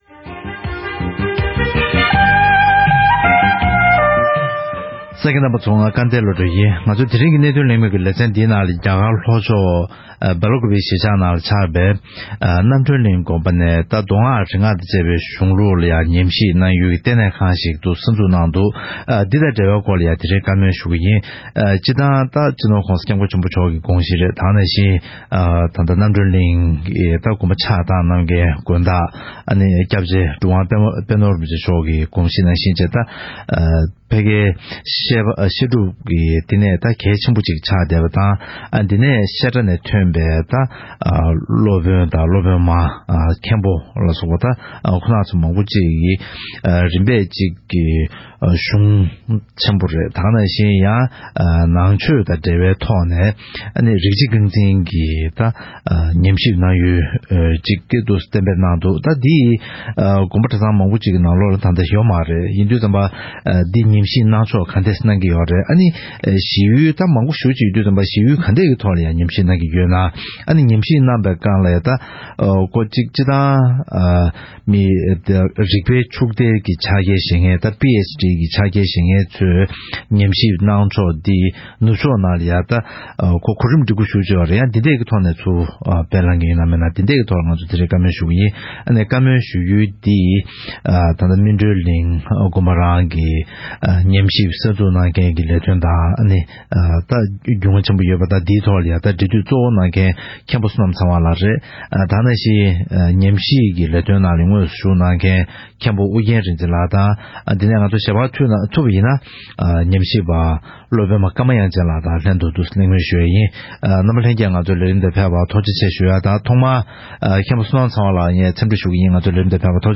བཀའ་མོལ་ཞུས་པར་གསན་རོགས།